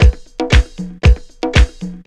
C3HOUSE116.wav